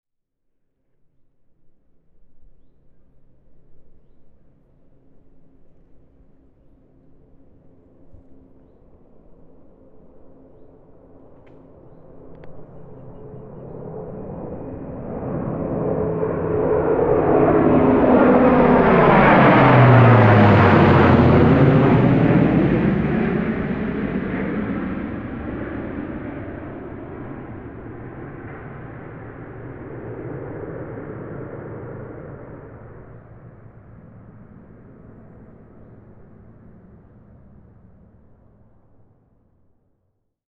Plutôt que de nous appuyer sur des banques sonores formatées, nous privilégions la capture directe des sons sur le terrain.
Son – Passage d’un avion de ligne
Avion.mp3